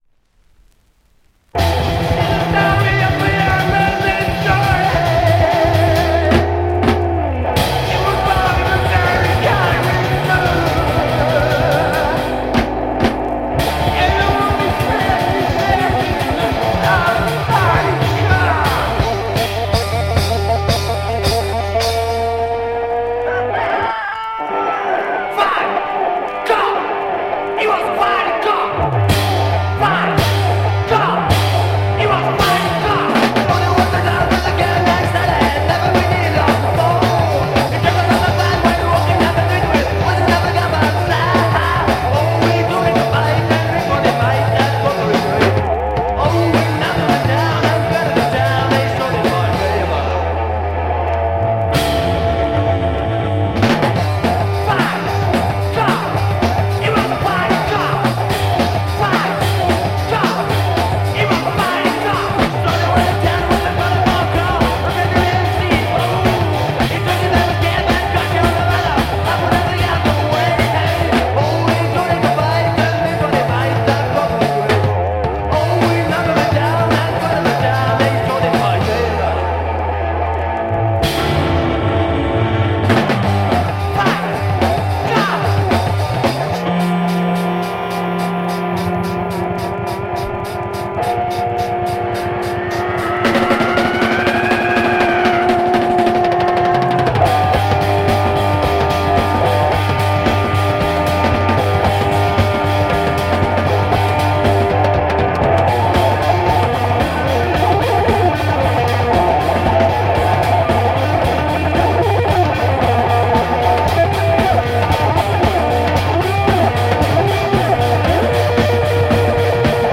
Killer French prog rock smasher
Rarest single by them, and th heaviest one aswell